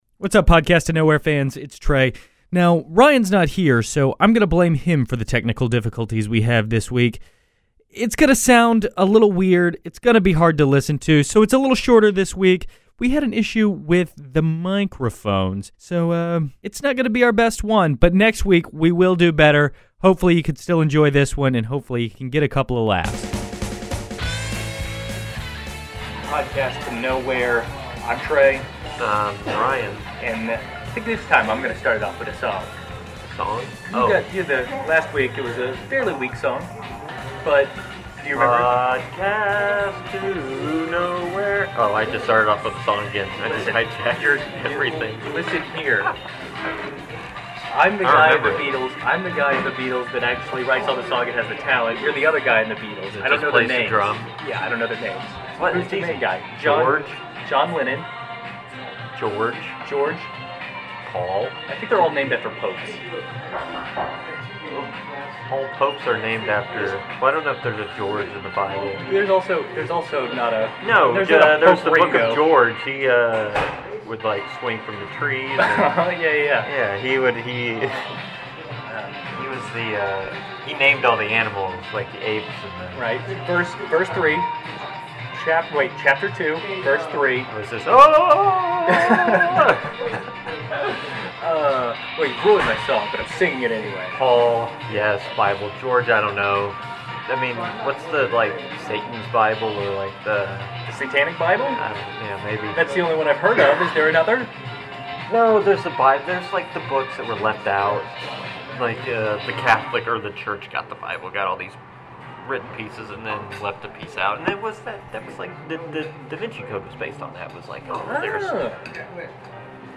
Yes, it sounds terrible.